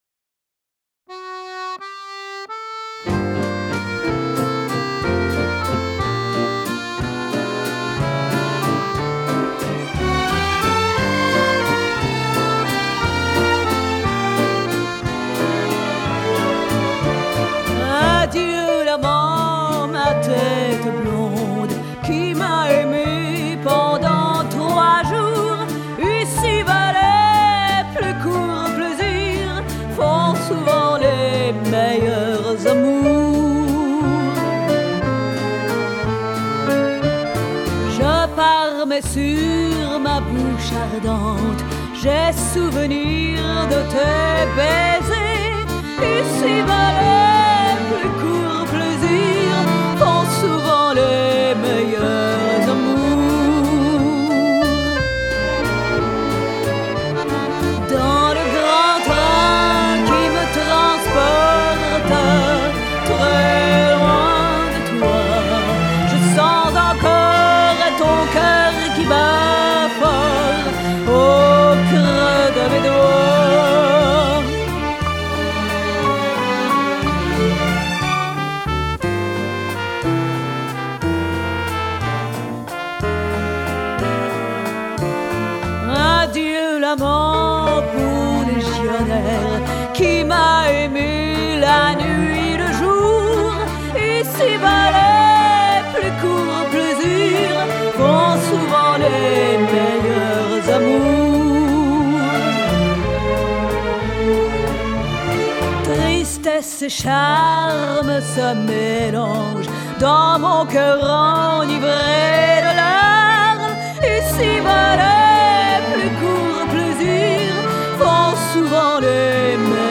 duygusal neşeli eğlenceli şarkı.